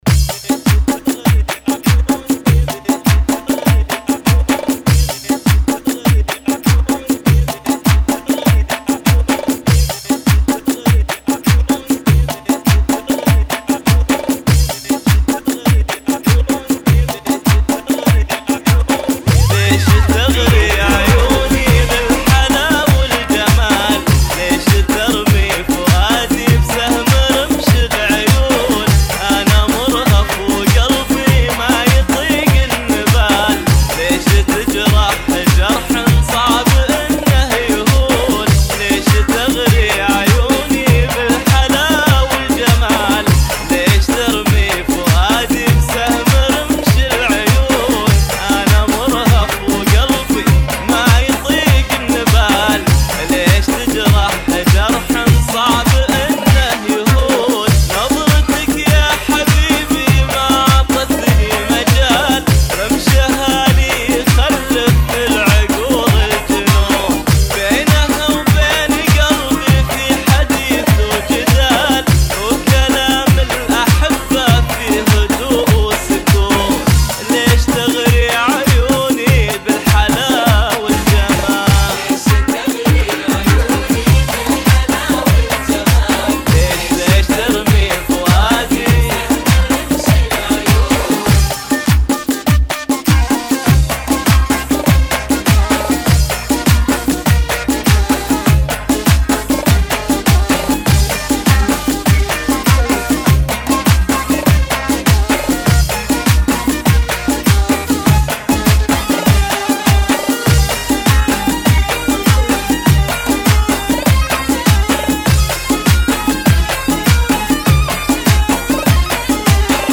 [ 100 bpm ] FunKy